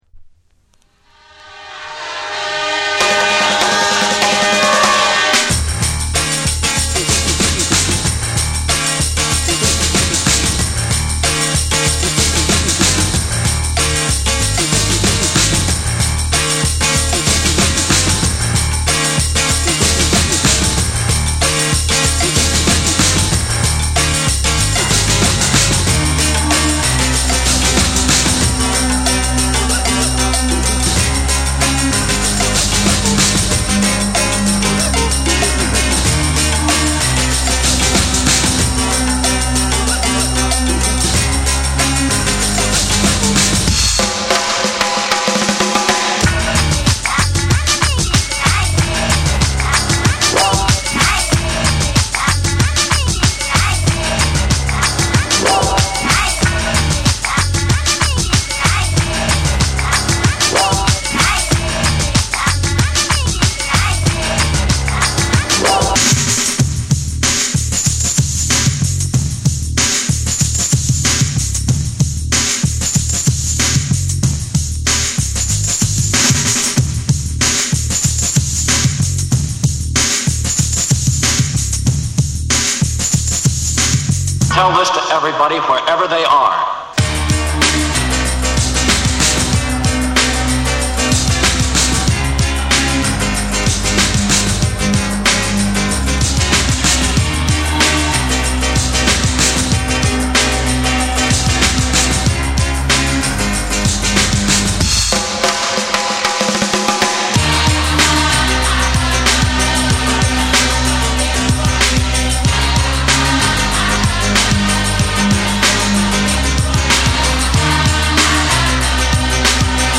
TECHNO & HOUSE / BREAKBEATS / ORGANIC GROOVE